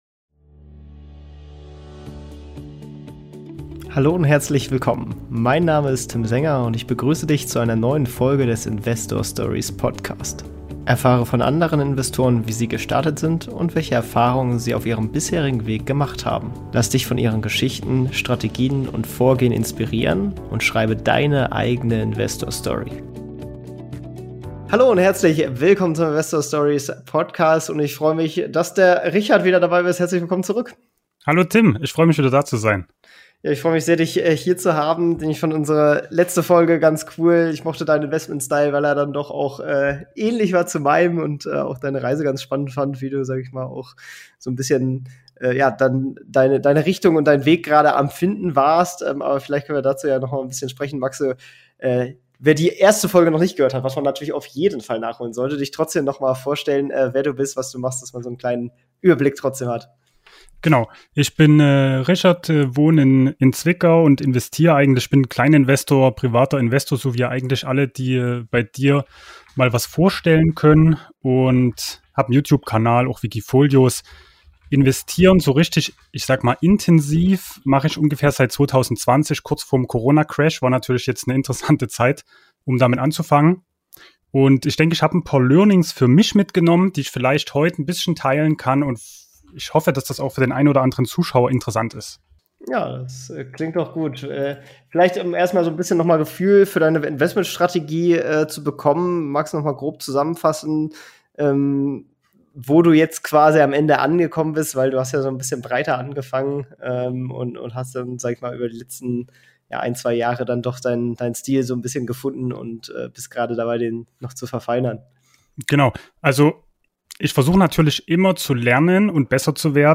Was seine Strategien mit Zigarrenstummeln und Kaffeedosen zu tun haben, erfährst du in diesem Interview.